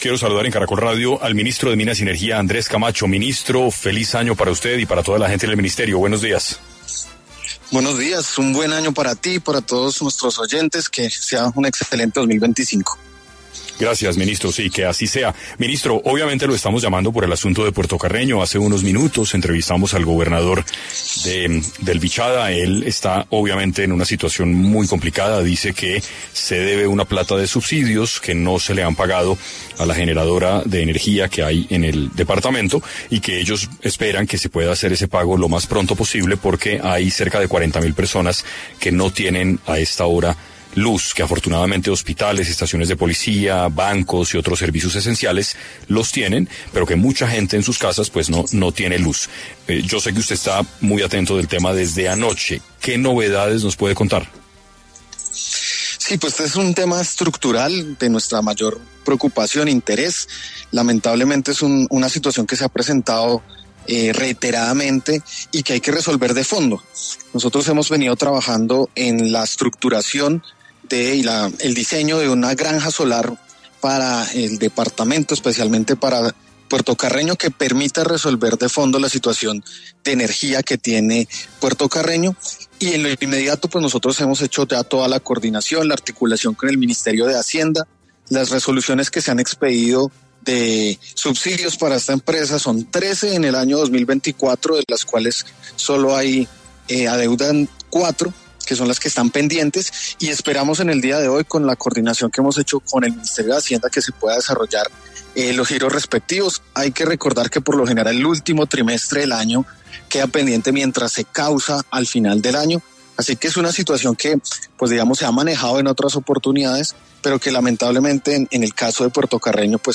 En Caracol Radio estuvo Andrés Camacho, ministro de Minas, indicando que considera que el paro que podrían realizar los camioneros no sería necesario